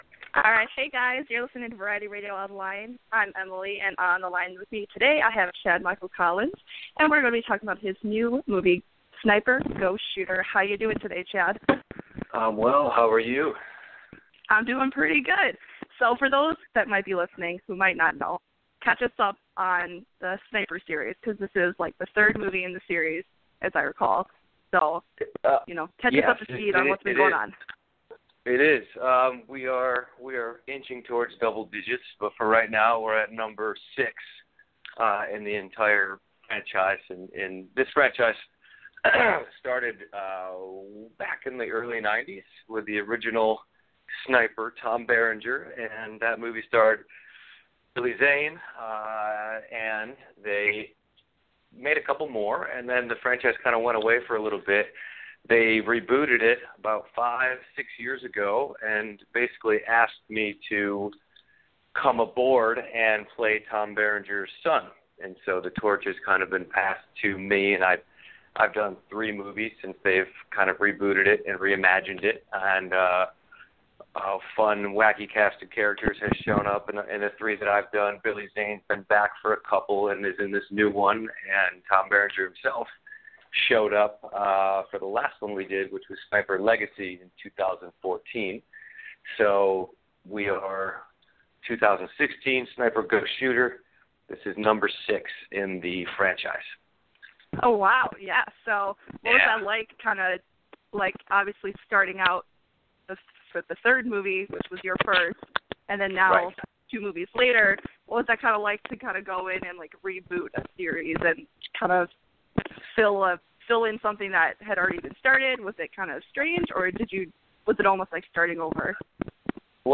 Chad Michael Collins "SNIPER: GHOST SHOOTER" Interview